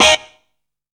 CHILDS STAB.wav